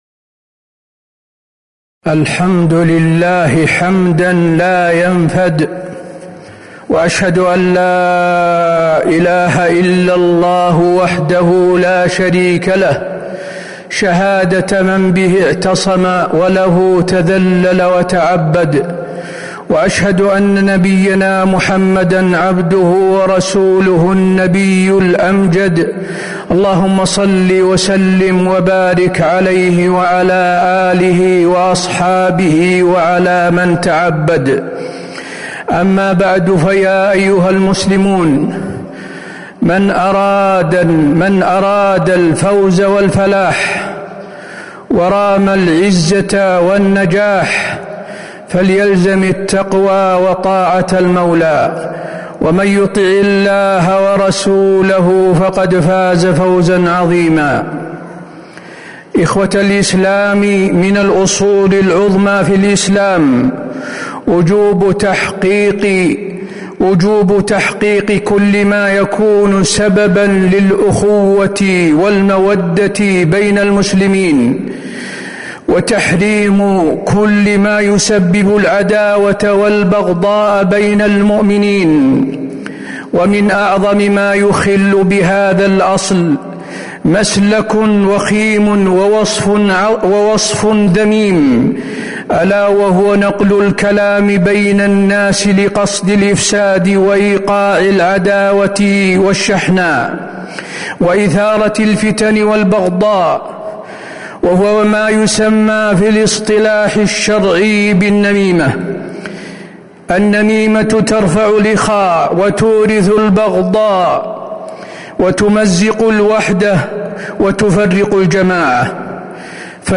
تاريخ النشر ٢٣ محرم ١٤٤٧ هـ المكان: المسجد النبوي الشيخ: فضيلة الشيخ د. حسين بن عبدالعزيز آل الشيخ فضيلة الشيخ د. حسين بن عبدالعزيز آل الشيخ النميمة حكمها وأضرارها